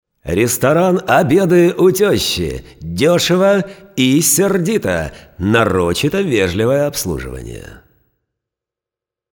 Рекламные интонации на примере шуточных объявлений: